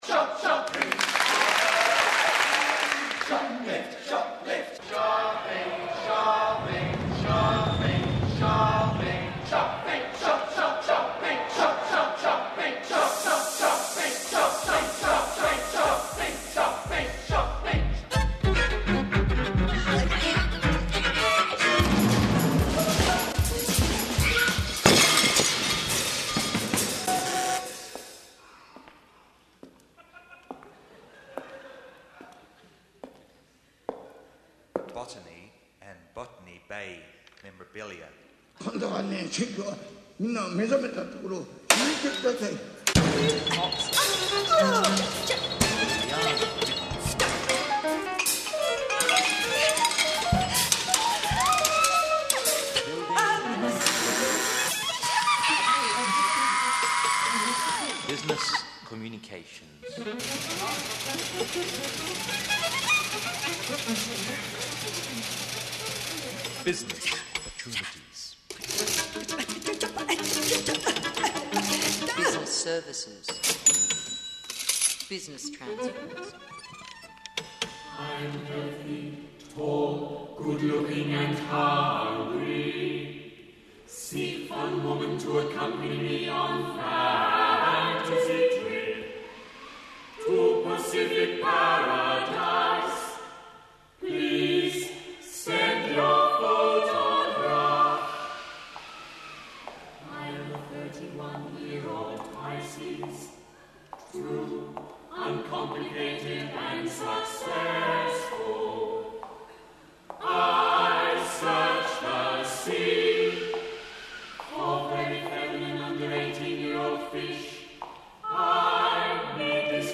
ABC live broadcast